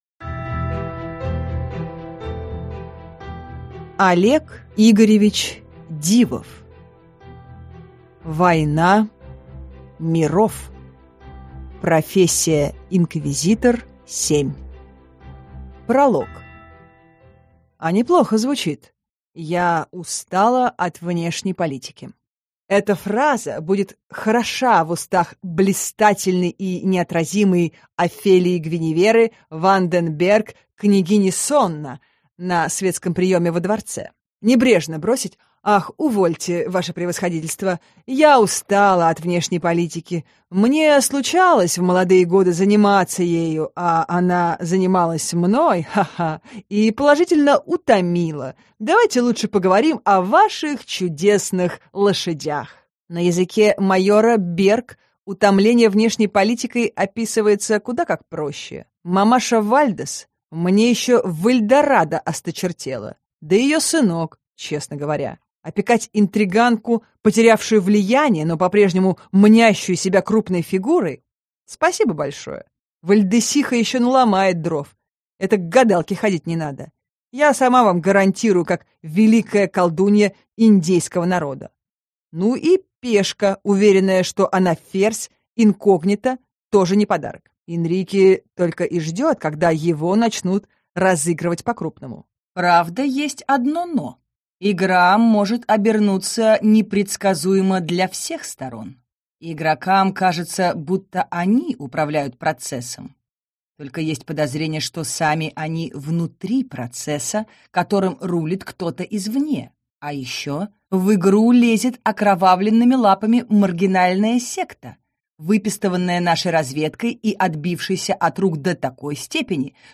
Аудиокнига Война миров | Библиотека аудиокниг